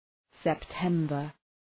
Προφορά
{sep’tembər}